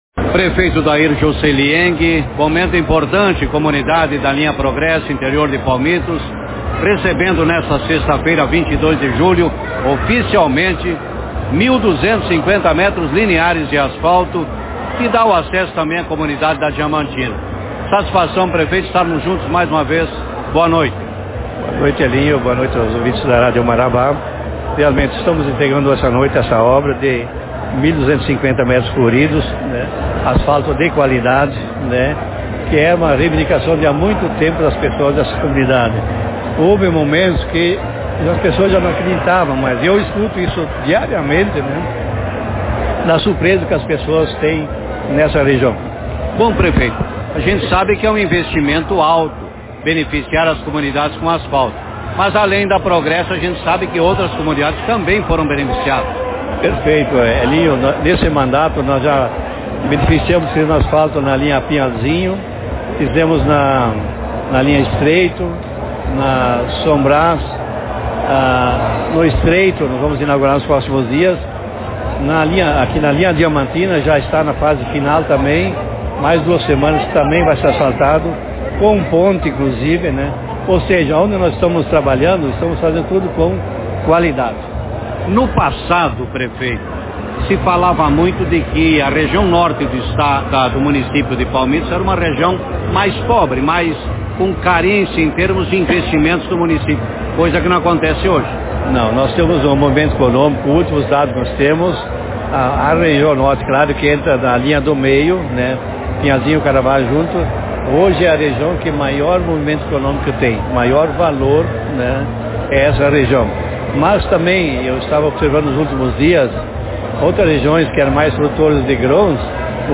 Solenidade maraca a entrega oficial de 1 KM de 250m de asfalto ao acesso a Linha Progresso, município de Palmitos Autor: Rádio Marabá 25/07/2022 Manchete Na noite da última sexta-feira,22, ocorreu a entrega oficial de 1Km e 250m de asfalto ao acesso a Linha Progresso, interior de Palmitos. Acompanhado de secretários municipais, vereadores e lideranças, moradores da comunidade da Progresso e localidades próximas, prestigiaram o evento e o pronunciamento do prefeito Dair Joceli Enge.